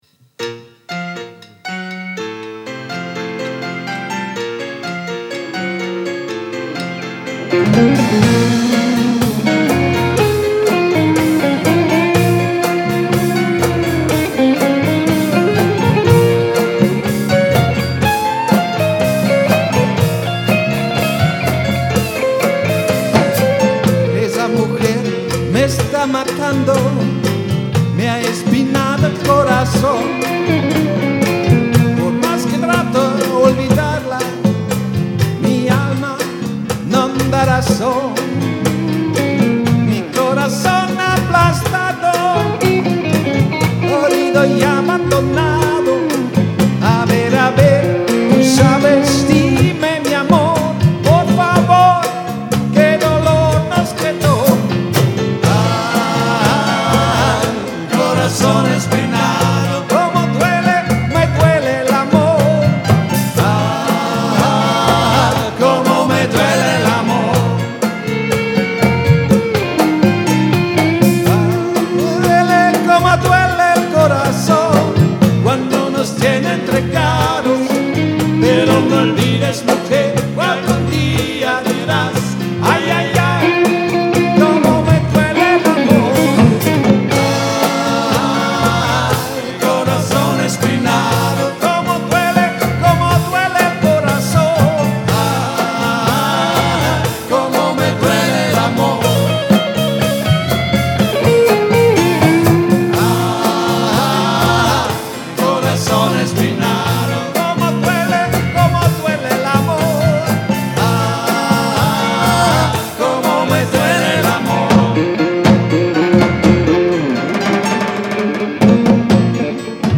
absolut Livemusic